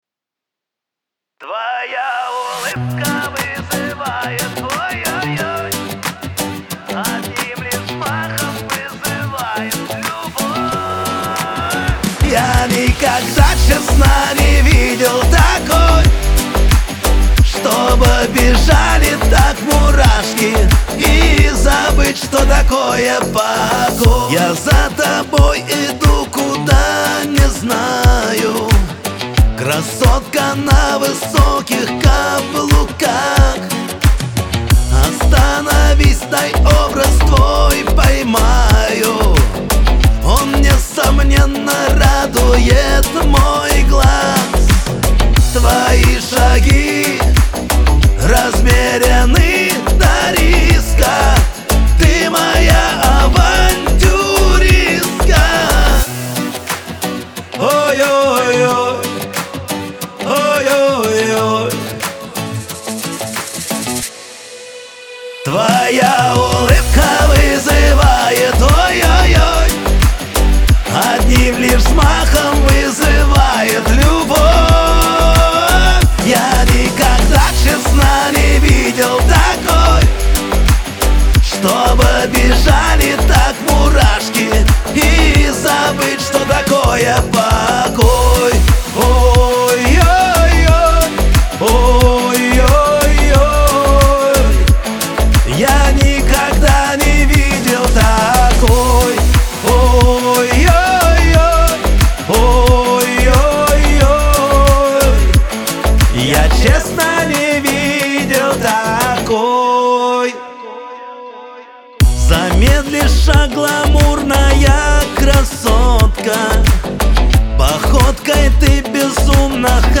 Веселая музыка
pop
диско , эстрада , dance